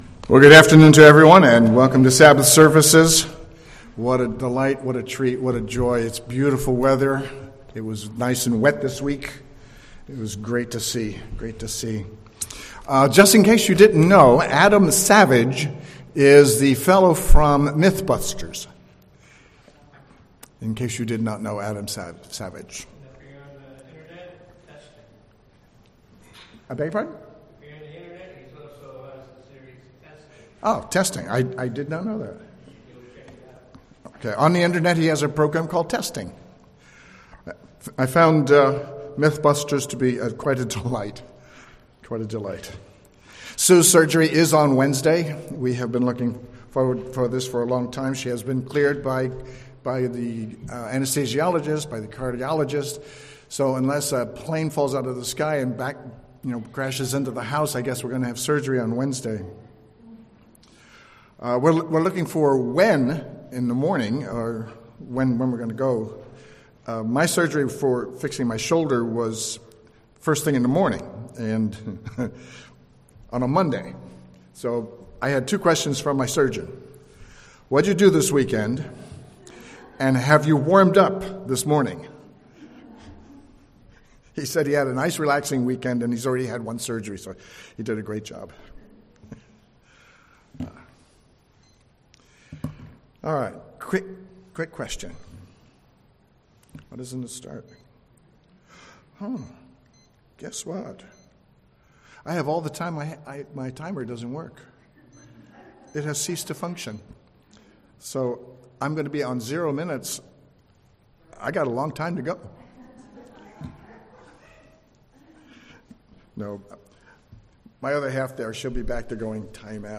Sermons
Given in San Jose, CA